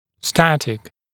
[‘stætɪk][‘стэтик]статичный, неподвижный, неизменный